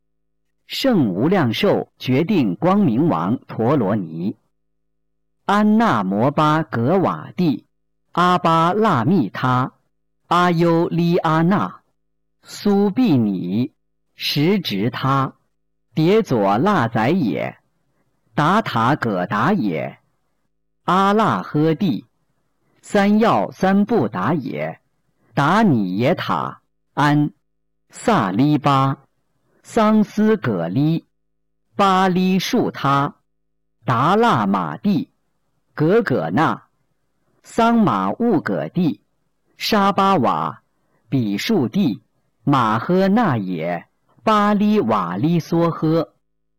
012《圣无量寿决定光明王陀罗尼》教念男声